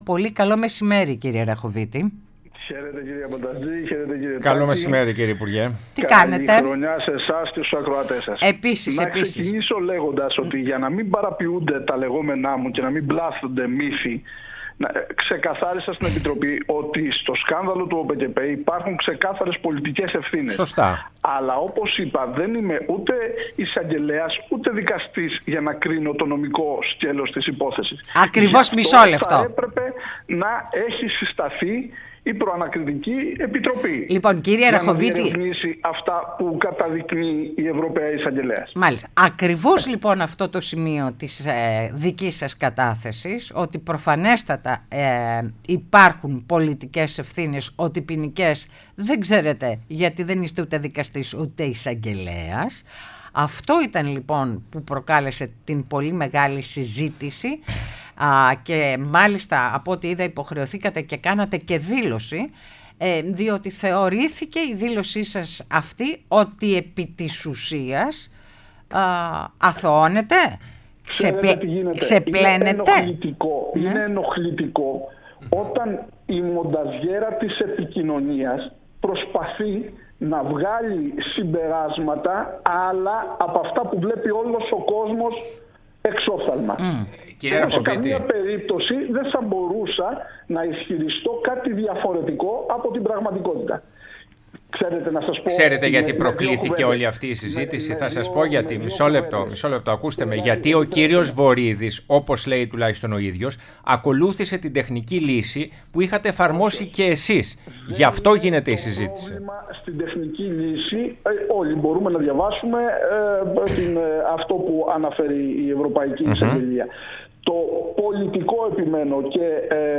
Ο πρώην υπουργός Αγροτικής Ανάπτυξης Σταύρος Αραχωβίτης, μίλησε στην εκπομπή “Ναι μεν αλλά”